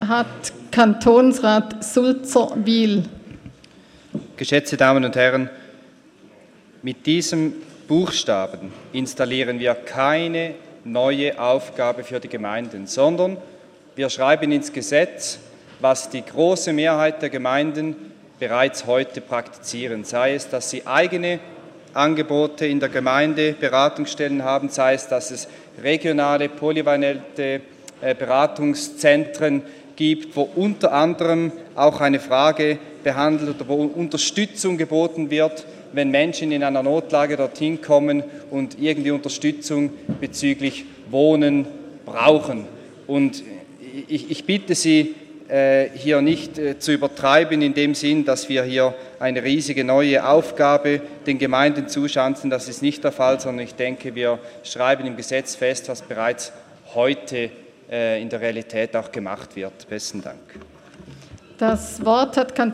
17.9.2018Wortmeldung
Session des Kantonsrates vom 17. bis 19. September 2018